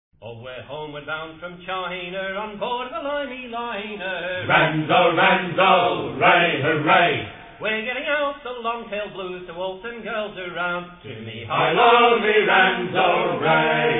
in front of a select audience